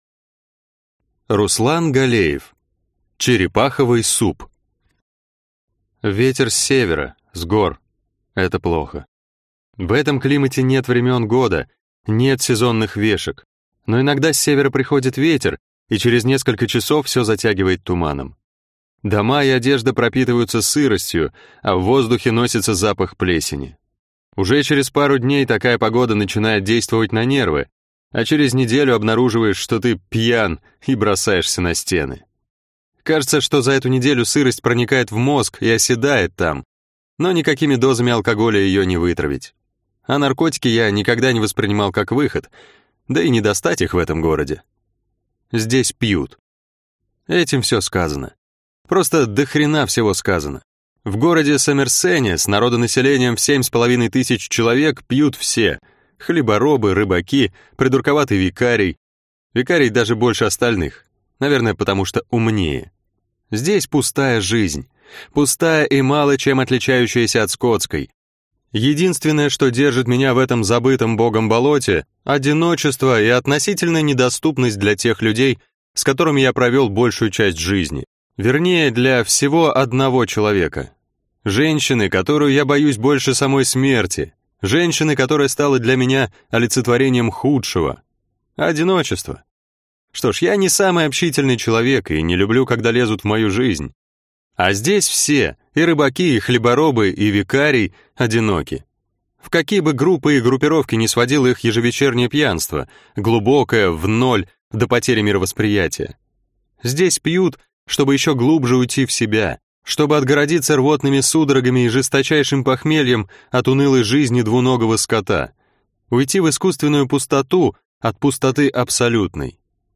Аудиокнига Черепаховый суп | Библиотека аудиокниг